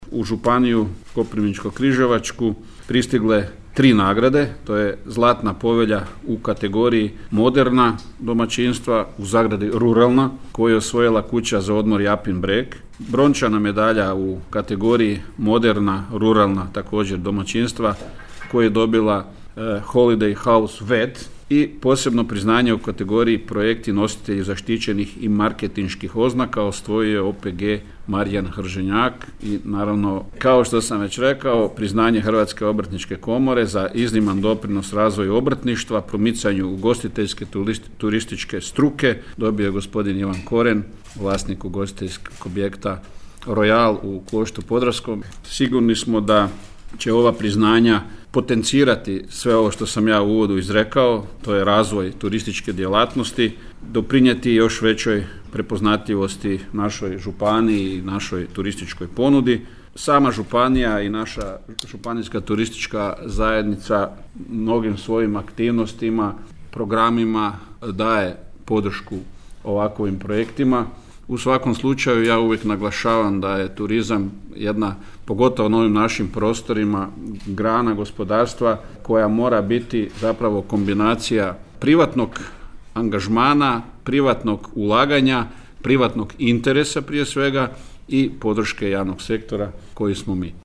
– kazao je župan Koren.